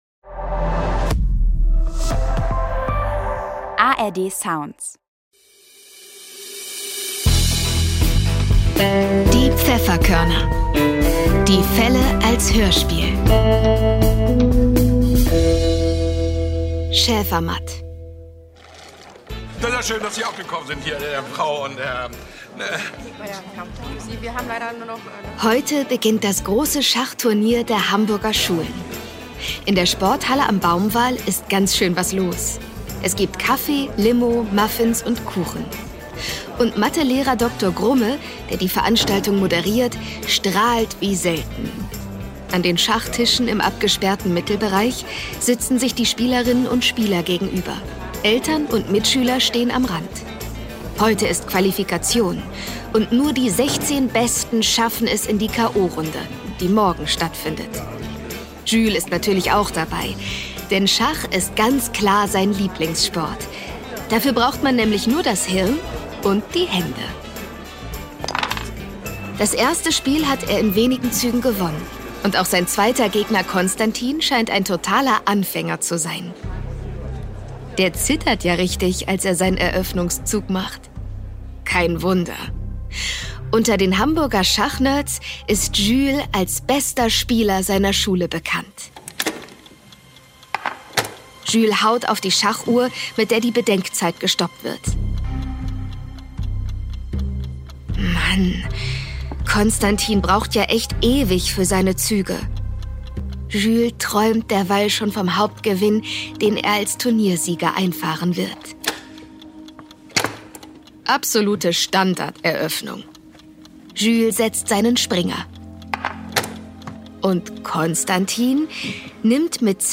Schäfermatt (14/21) ~ Die Pfefferkörner - Die Fälle als Hörspiel Podcast